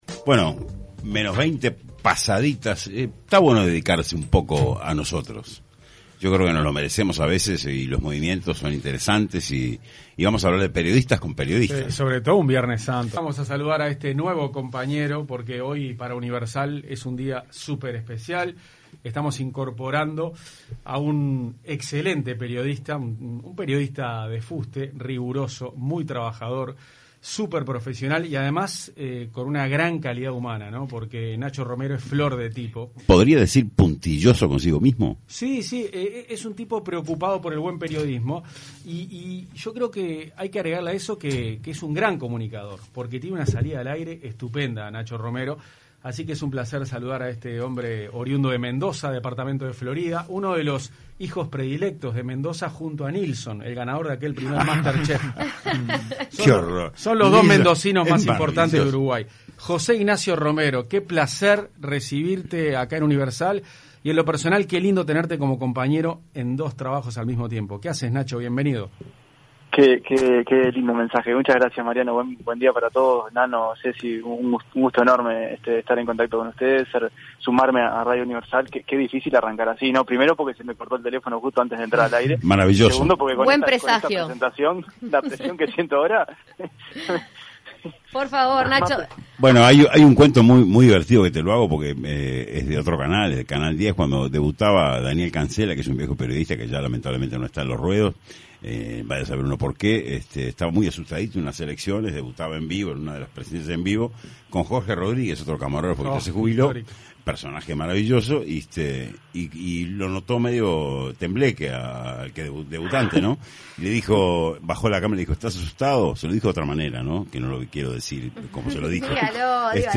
Escuche la entrevista de Punto de Encuentro